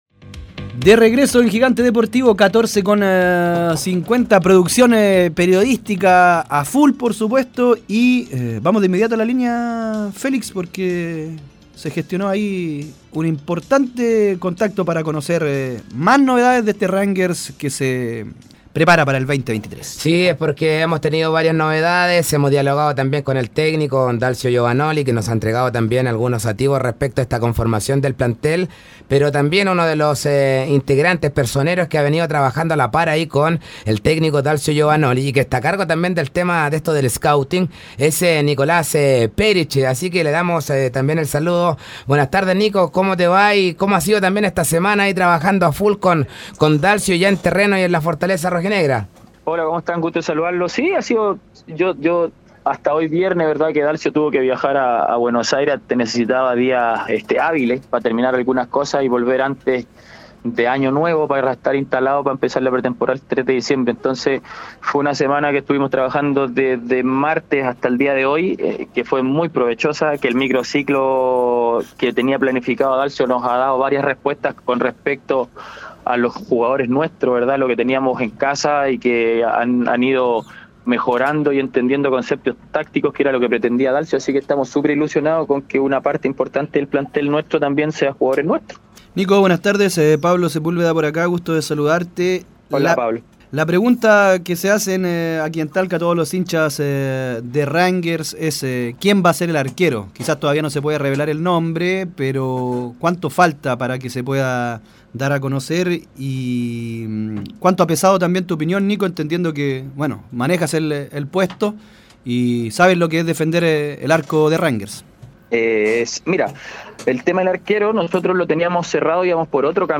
Tres refuerzos cerrados y otro cerca de confirmarse en Rangers: esas son las novedades que adelantó, en diálogo con Gigante Deportivo, Nicolás Peric, hoy desempeñando tareas de scouting en la tienda del Piduco.